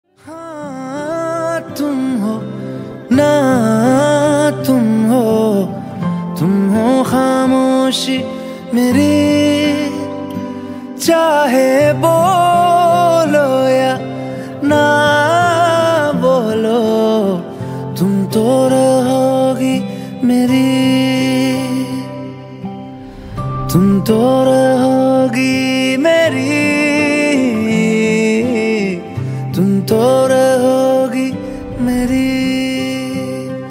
Categories Hindi ringtones